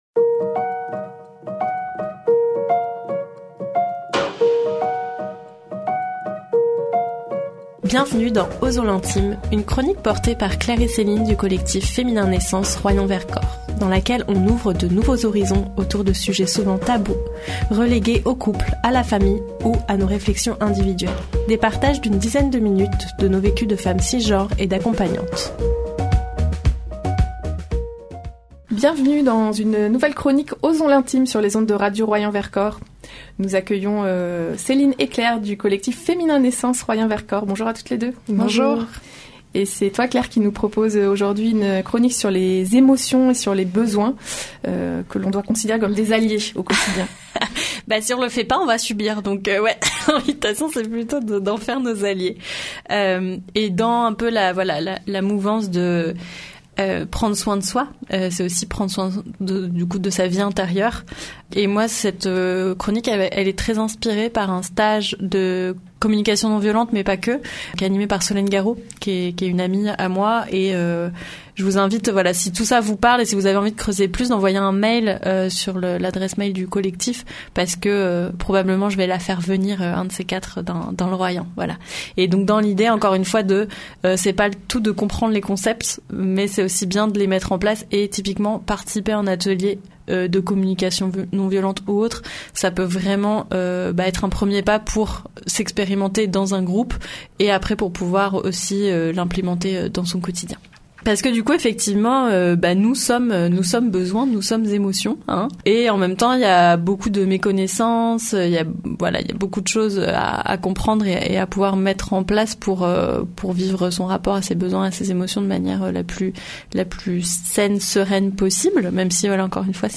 Des partages d’une dizaine de minutes autour de leurs vécus de femmes cisgenres et d’accompagnantes.